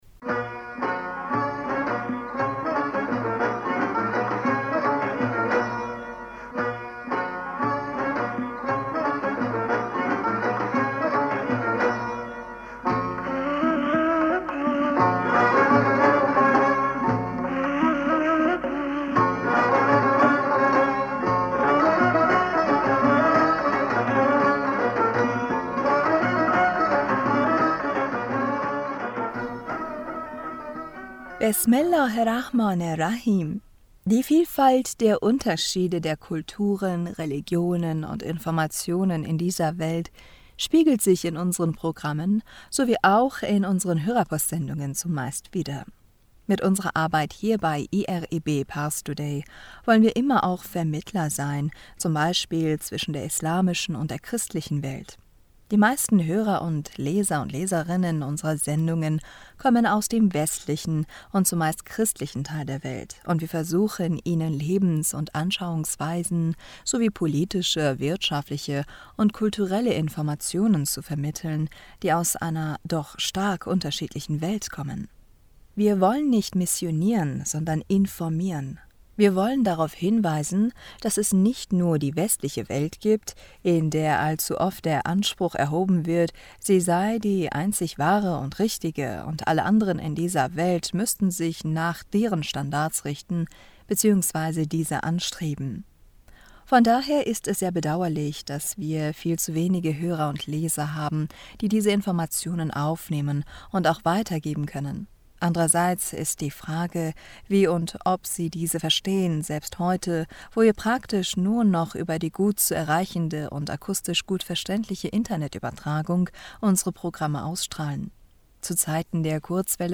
Hörerpostsendung am18. September 2022 Bismillaher rahmaner rahim - Die Vielfalt und Unterschiede der Kulturen, Religionen und Informationen in dieser Welt...